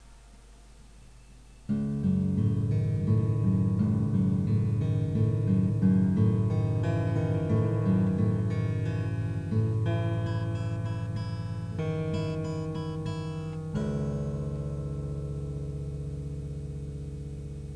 если это - настройка, то я - кактус